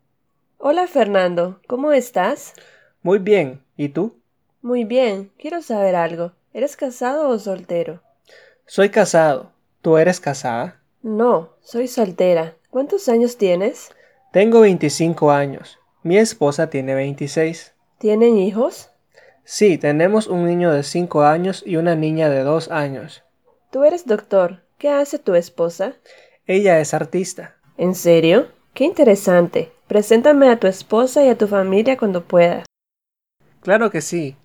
Hola a todos, En este primer episodio vamos a escuchar una presentación personal general en español, repetirla y practicarla tantas veces como sea necesario.